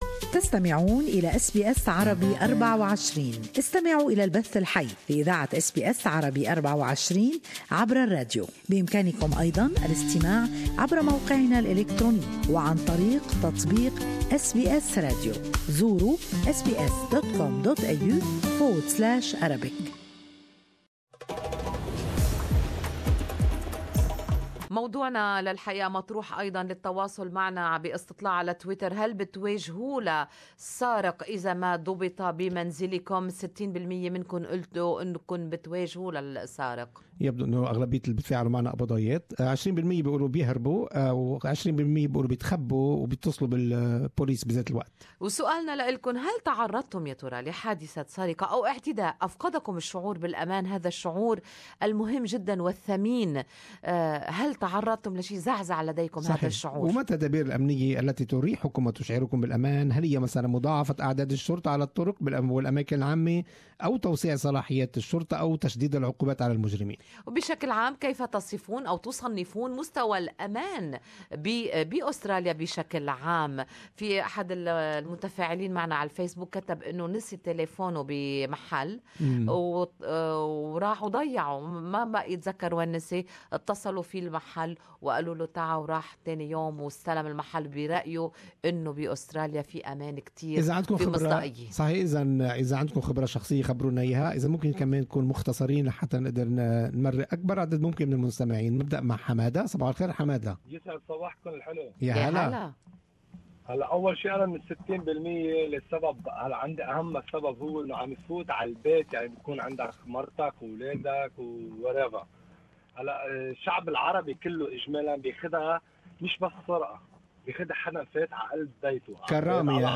In light of the burglaries and attacks happening around Australia and specifically Melbourne in the past few months, mainly by the Apex gang, Good Morning Australia asked this on its listeners who shared their views and experiences.